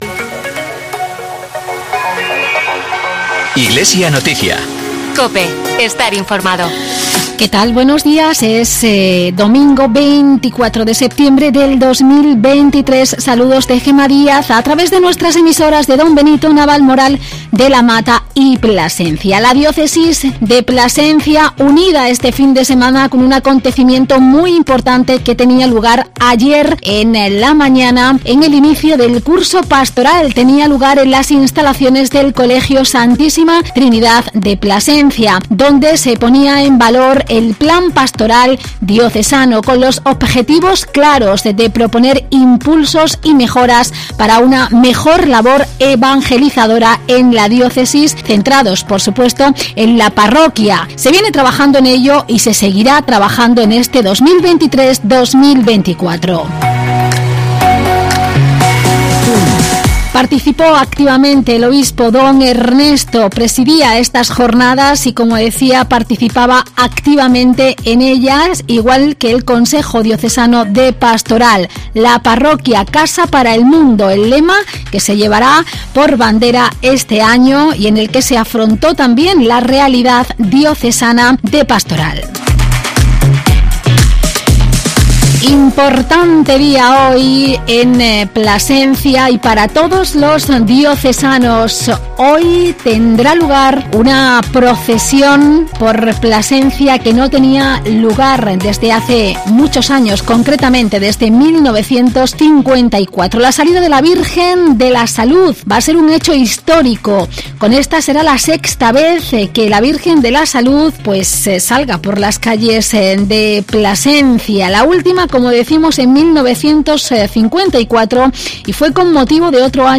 Informativo de la diócesis de Plasencia con toda la actualidad, con la participación del sacerdote diocesano